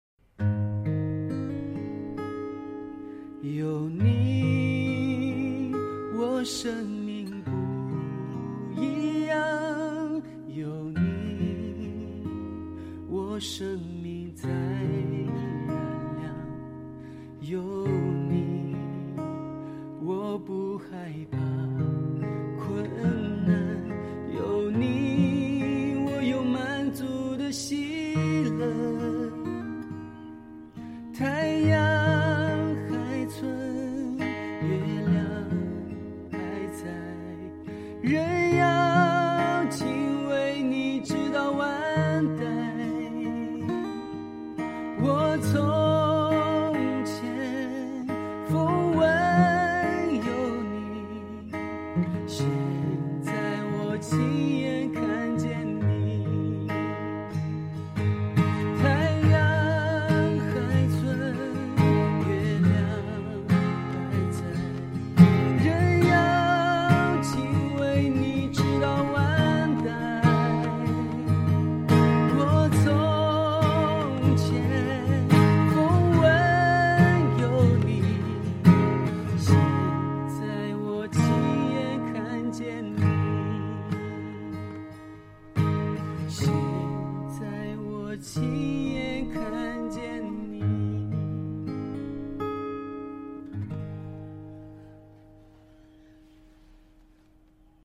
今天继续为大家带来我的天台音乐会。
这些日子，我就多给大家弹弹琴、唱唱歌，希望你们都能以喜乐的心去面对。